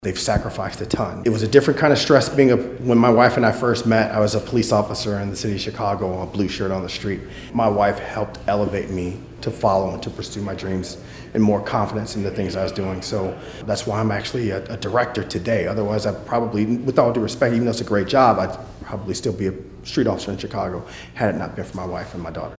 Following the ceremony, he met with media and says he is grateful for the opportunity to have his family in attendance — noting he tried to stay stoic, though the morning was one of emotion.